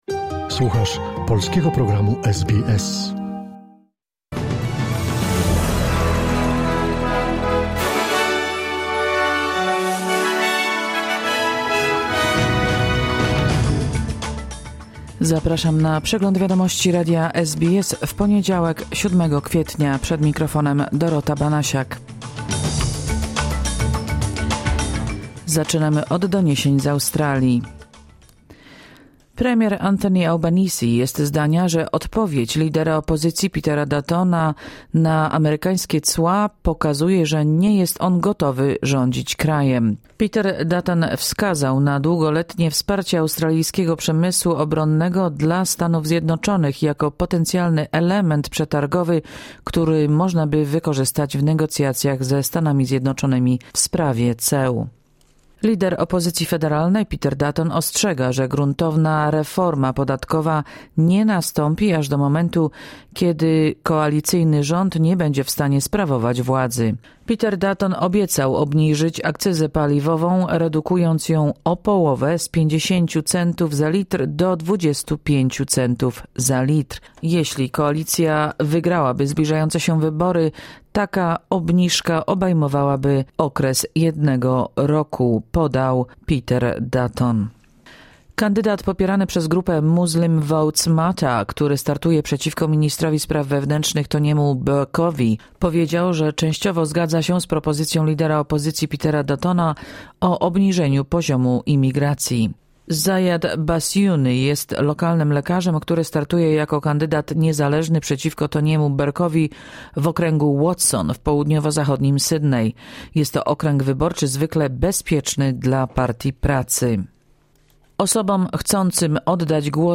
Wiadomości 7 kwietnia SBS News Flash